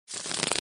发牌音效.MP3